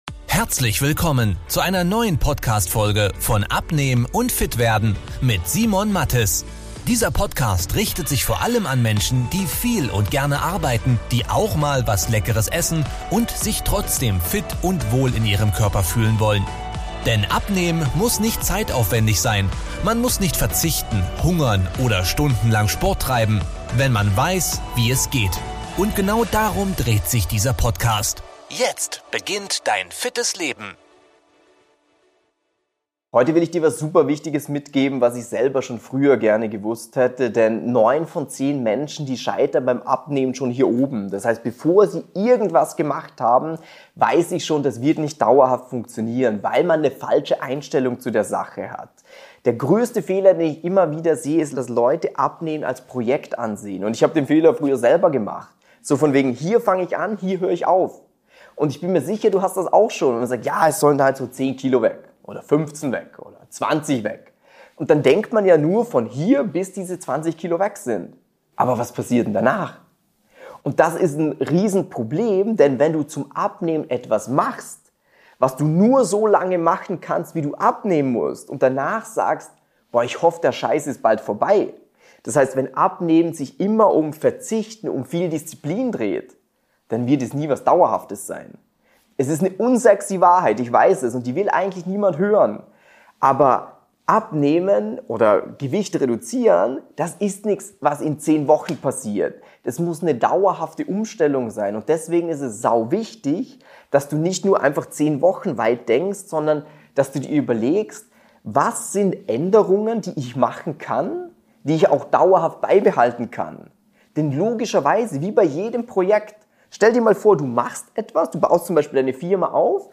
#382 Schnell und nachhaltig abnehmen, ohne Verzicht! - Kundeninterview